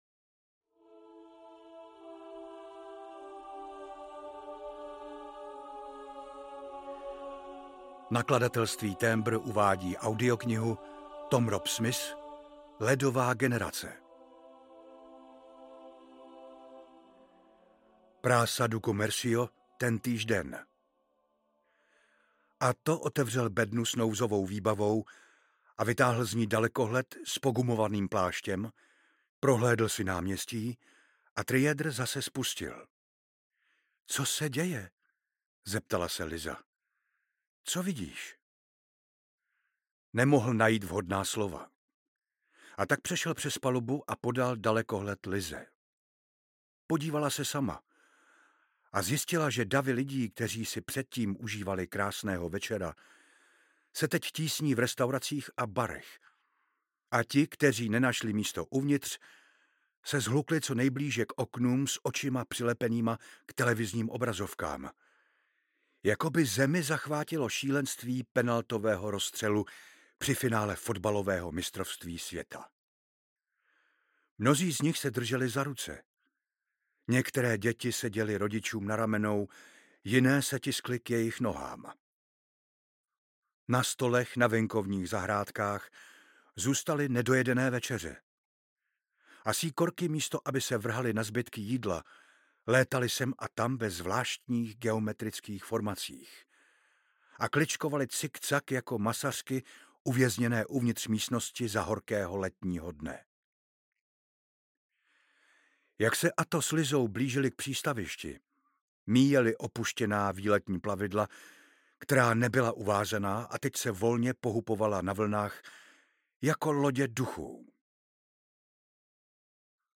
Ledová generace audiokniha
Ukázka z knihy